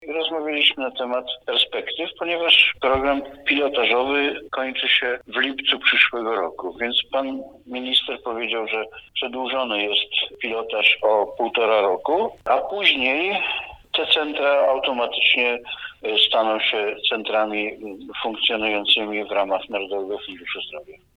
– My nasze centrum chcemy rozwijać – powiedział starosta Jerzy Sudoł